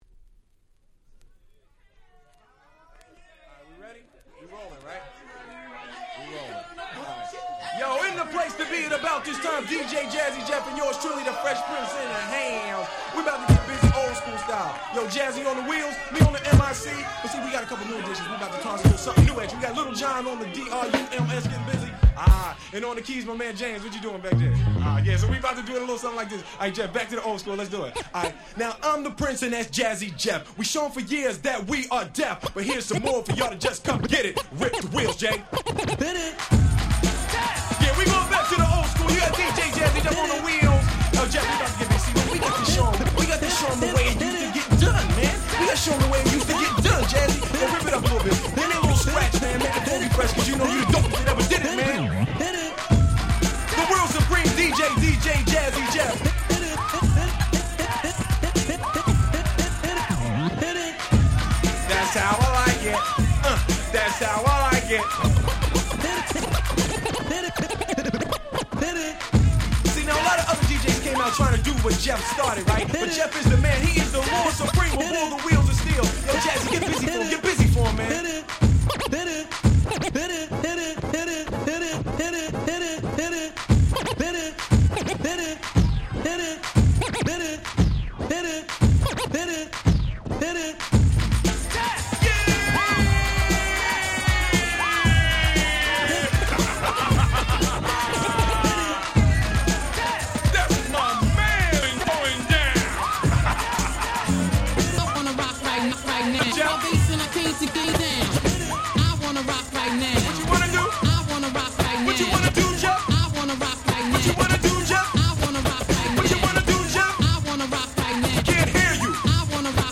94' Smash Hit Hip Hop !!